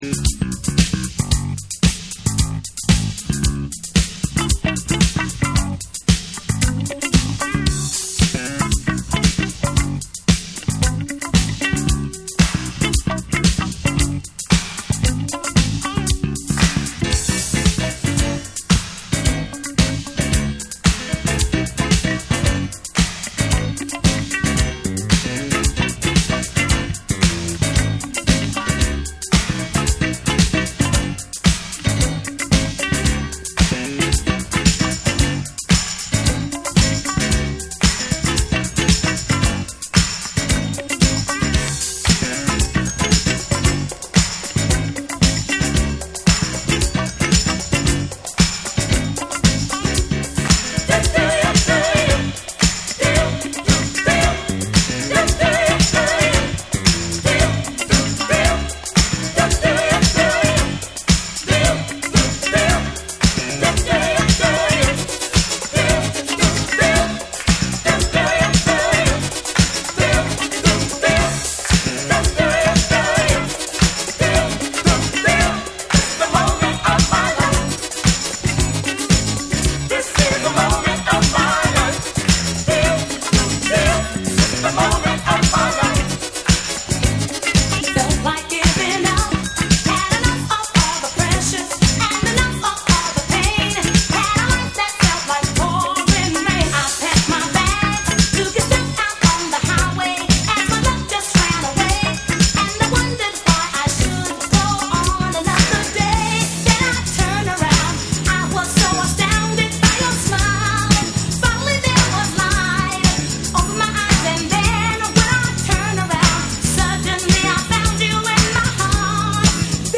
DANCE CLASSICS / DISCO